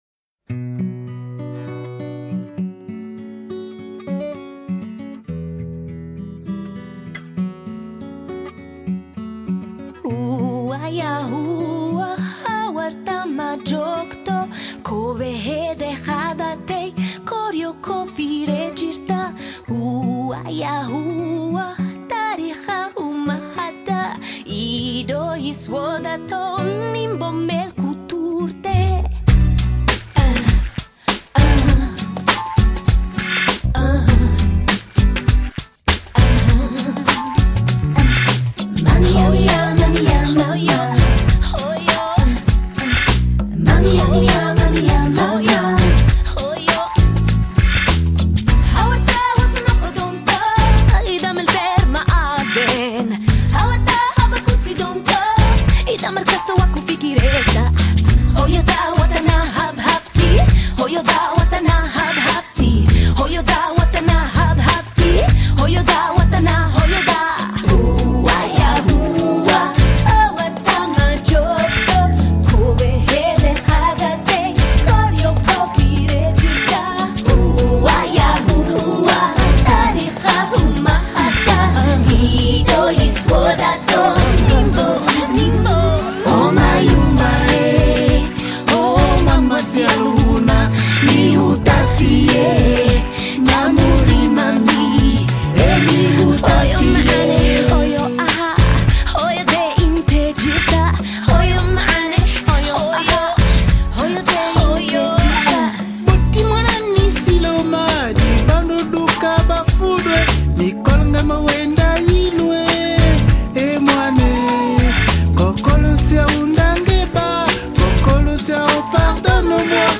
Жанр: Ethnic, World Music, Vocal